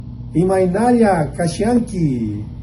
Imaynalla kasanki = IMA-EE-NA-ELA KA-SHAN-KEY